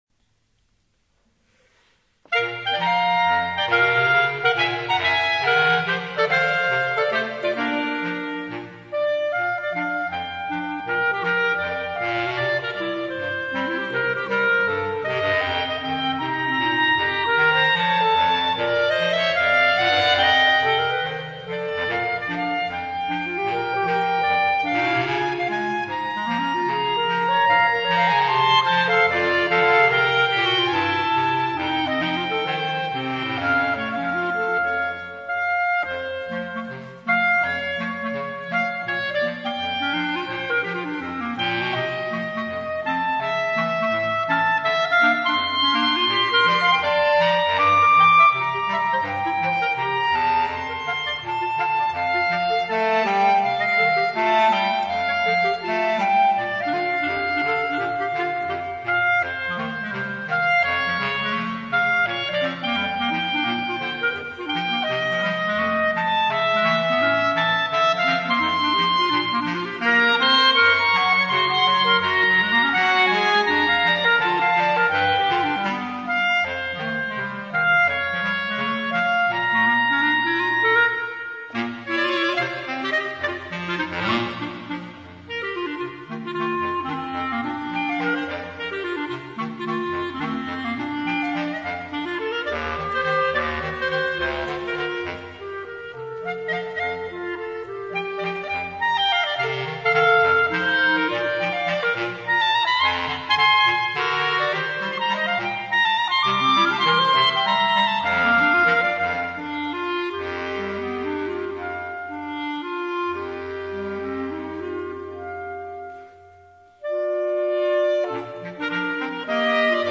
Per due clarinetti e clarinetto basso
Una bella fantasia per due clarinetti e clarinetto basso.